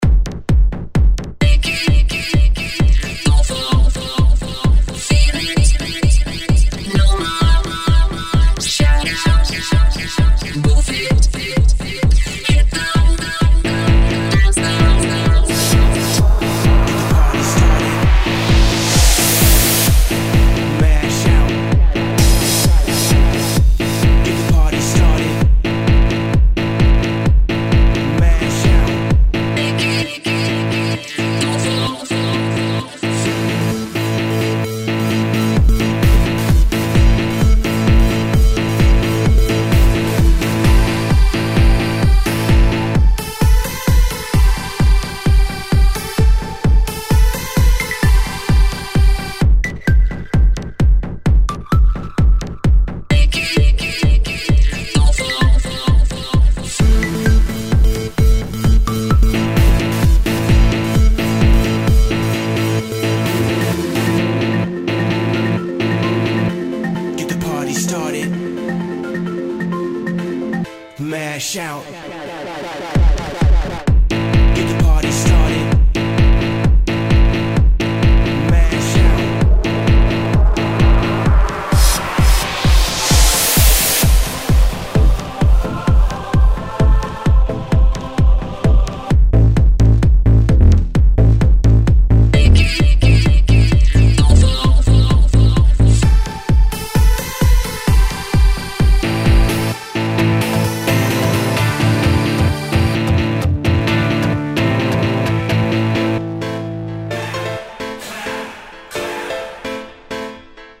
Electro
Electro.MP3